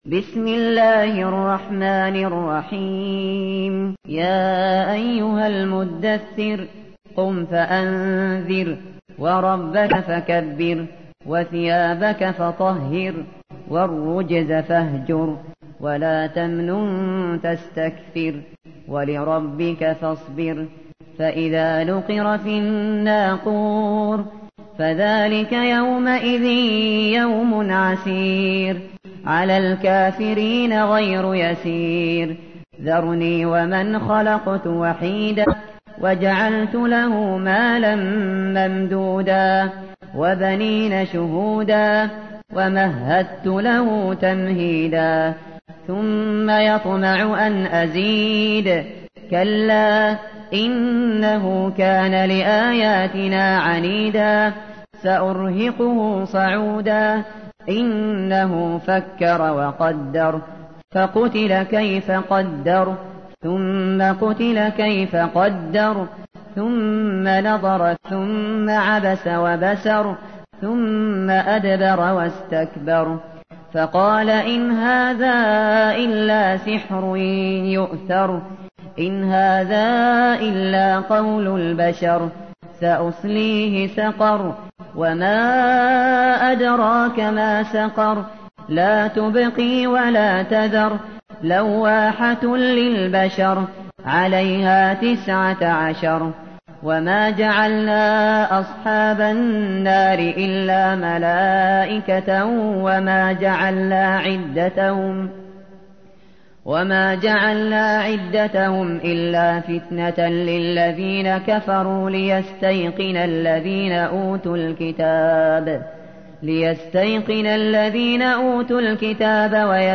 تحميل : 74. سورة المدثر / القارئ الشاطري / القرآن الكريم / موقع يا حسين